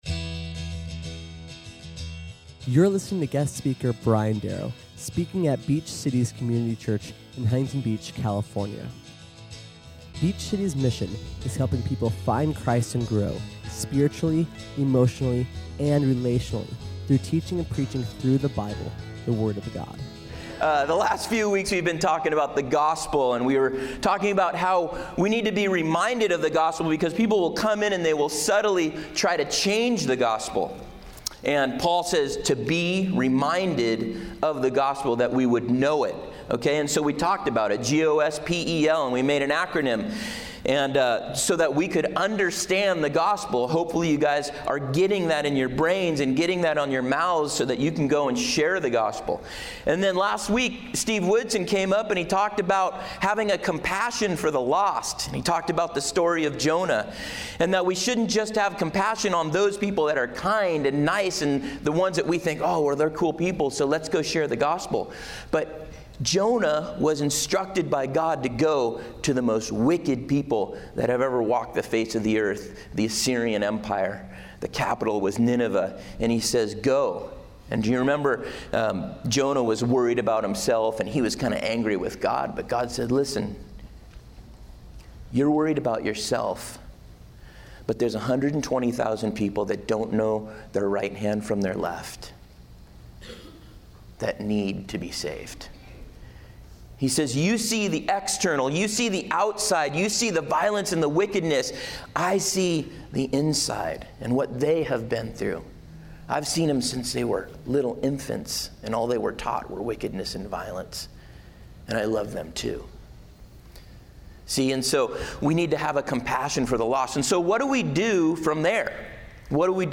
SERMON AUDIO: SERMON NOTES: